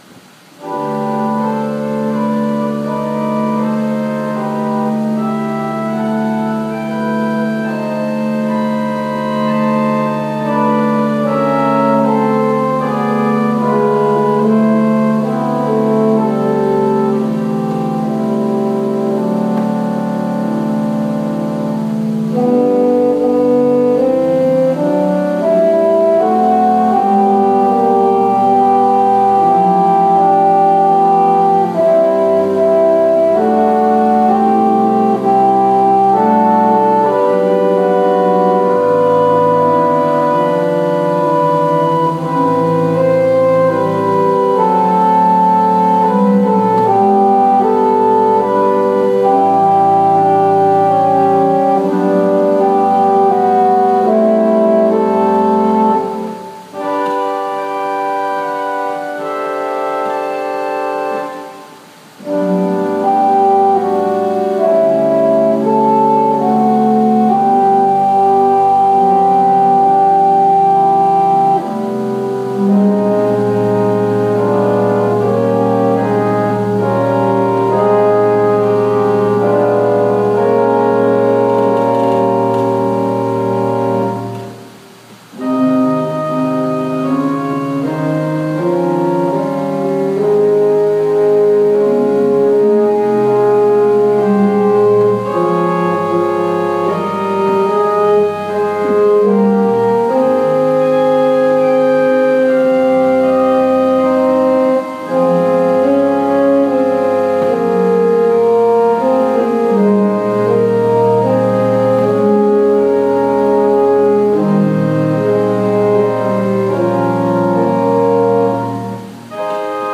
Worship Service July 12, 2020 | First Baptist Church, Malden, Massachusetts